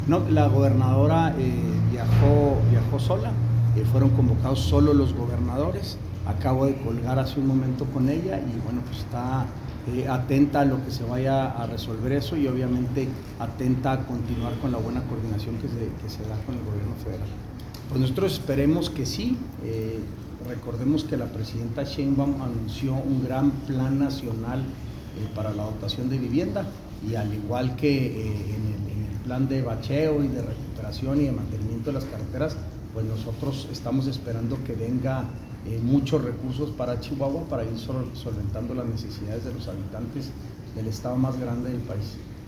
En cuanto a la posibilidad de que Campos y Sheinbaum discutan otros asuntos relevantes para Chihuahua, tal y como la destinación de mayores recursos federales, el secretario general de Gobierno, Santiago De La Peña, adelantó que la administración estatal no ha recibido nueva información más allá de la construcción de viviendas y el mantenimiento de las carreteras contemplado por el Bachetón.